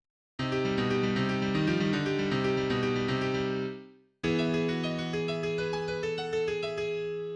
Hörbeispiele mit beiden Händen/?)